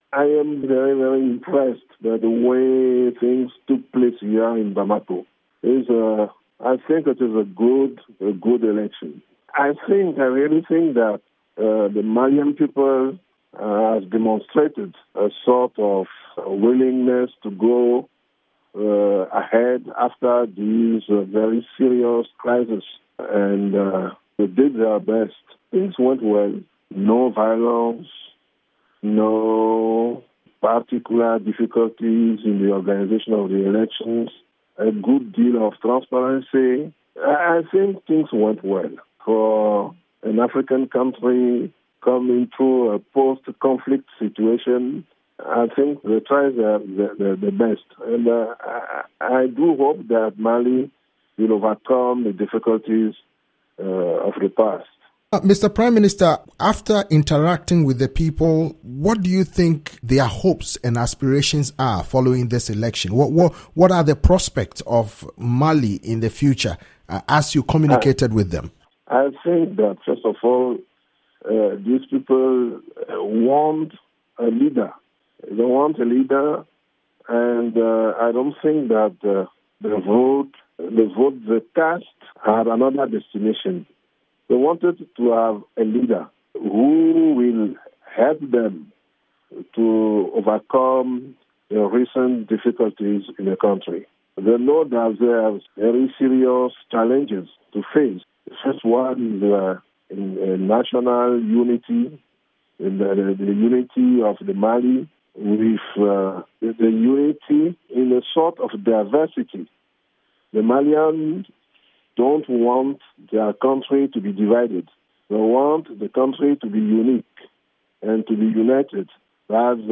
interview with Edem Kodjo, head of AU election monitoring mission